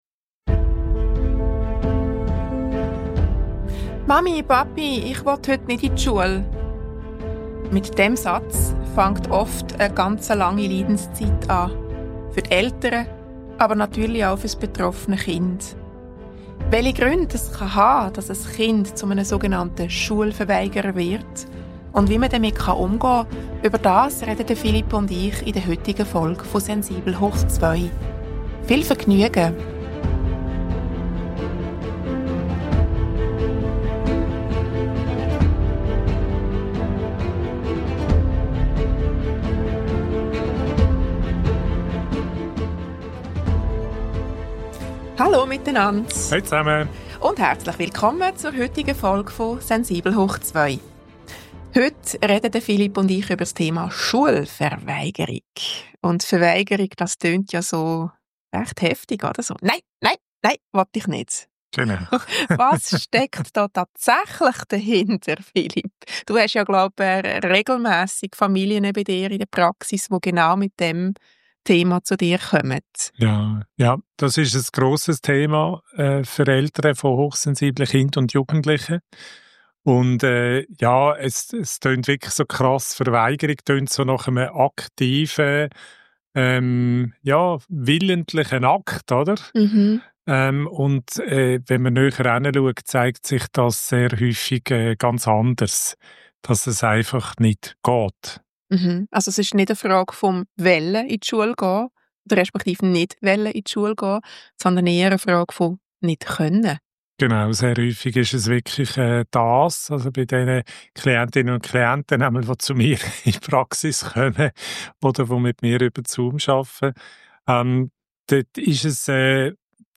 In dieser – dem Thema entsprechend auch etwas emotionalen – Folge sprechen wir darüber, wo mögliche Ursachen liegen können, welche Lösungswege realistisch sind und warum die Beziehung zum Kind dabei eine der stärksten Ressourcen überhaupt ist.